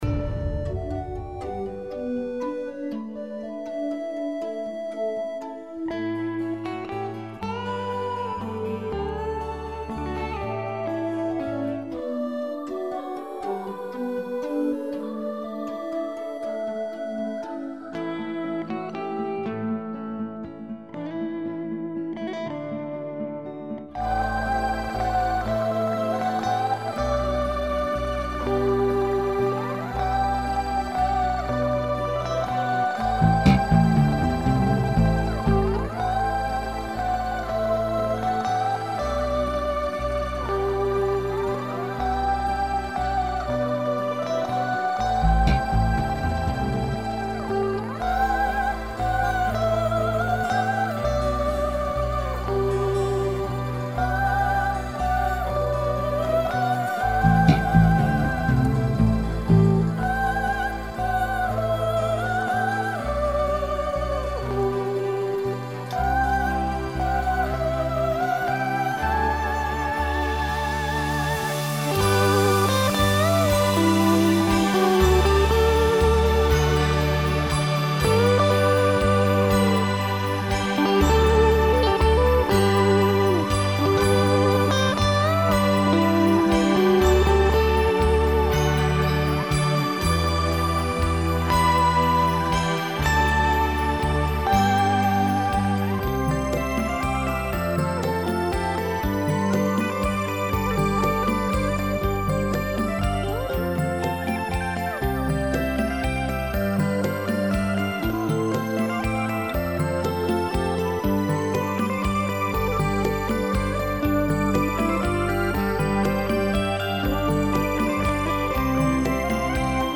С музыкой так спокойно получилось и миролюбиво :-)